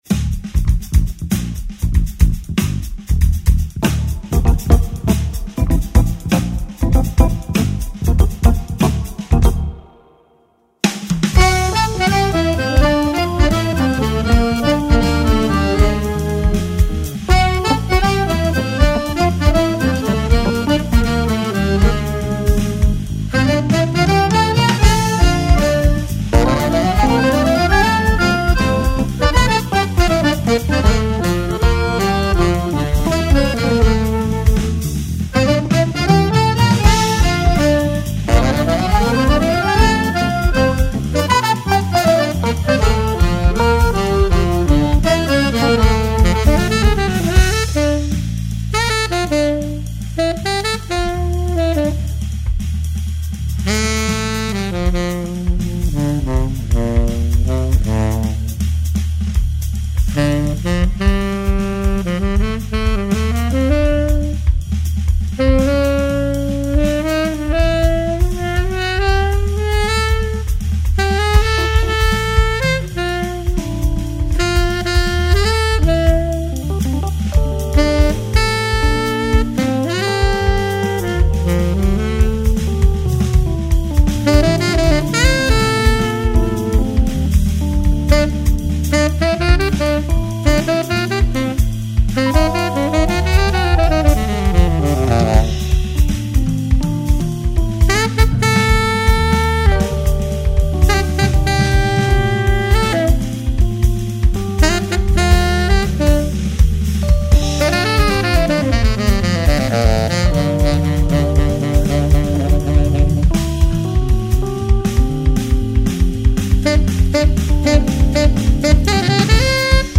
2912   09:33:00   Faixa: 8    Jazz